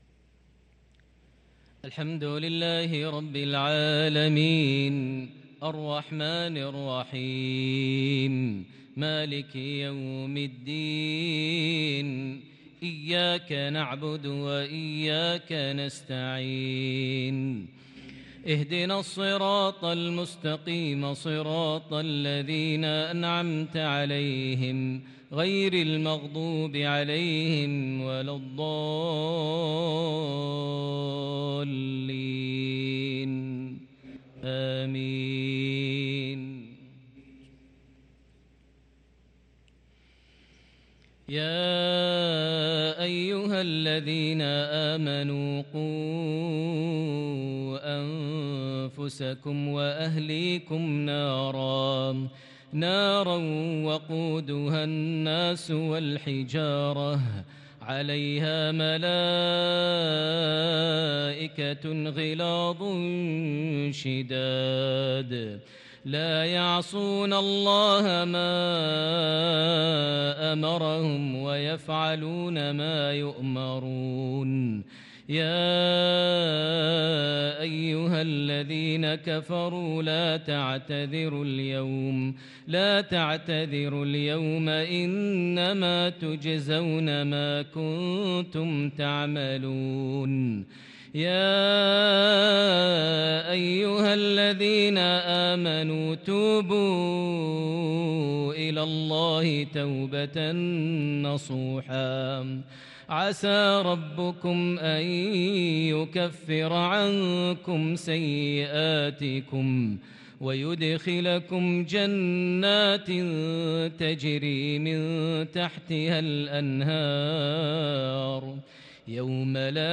صلاة العشاء للقارئ ماهر المعيقلي 13 ذو القعدة 1443 هـ
تِلَاوَات الْحَرَمَيْن .